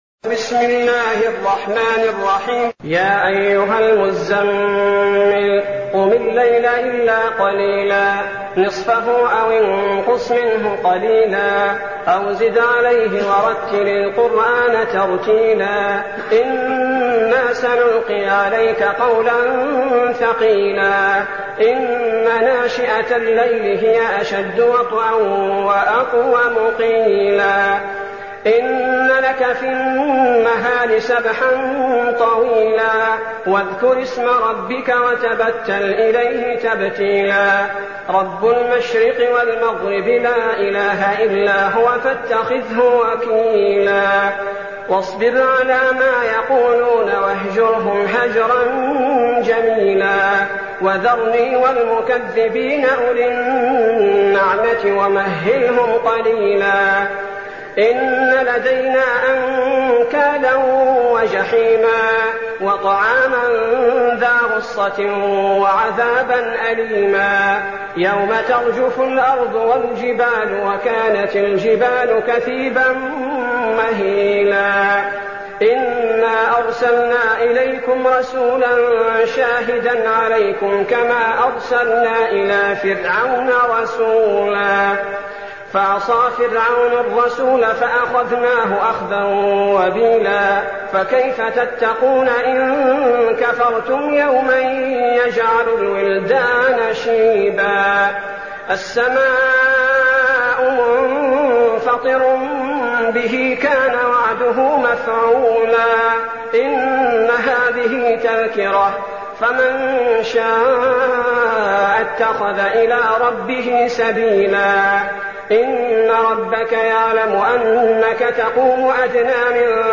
المكان: المسجد النبوي الشيخ: فضيلة الشيخ عبدالباري الثبيتي فضيلة الشيخ عبدالباري الثبيتي المزمل